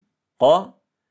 Alltså, tiden som behövs för att uttala قَ (Qa)